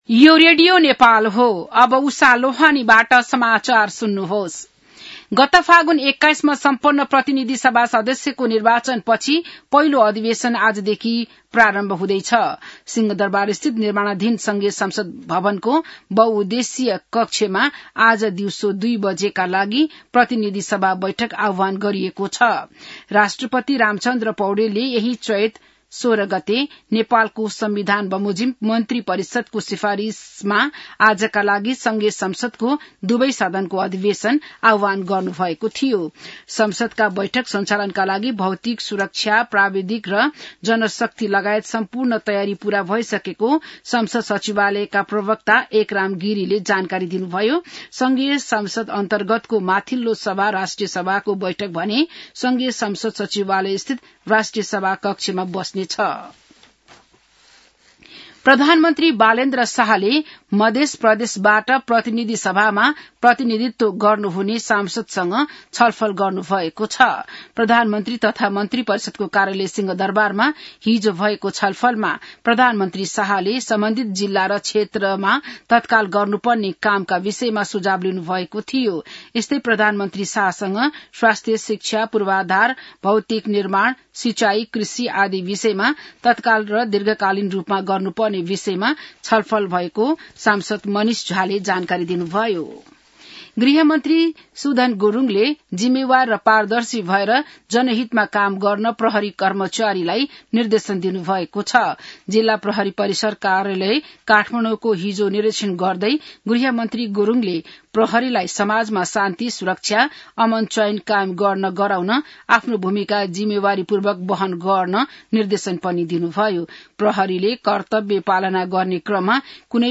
बिहान १० बजेको नेपाली समाचार : १९ चैत , २०८२